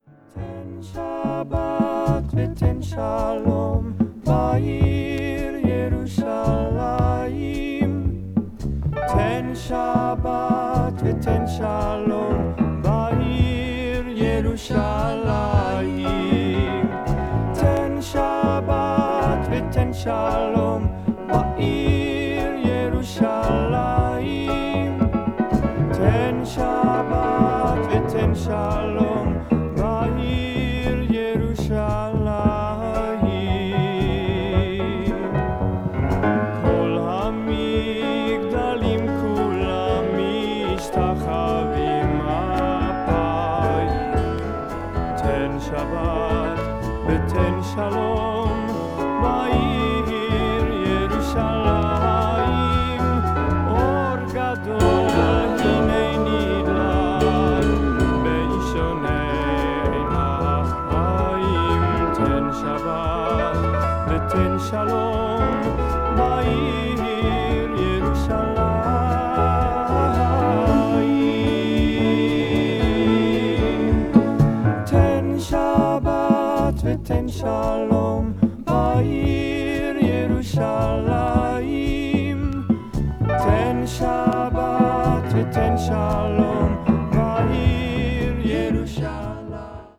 country   folk   funky rock   hebrew   psychedelic   r&b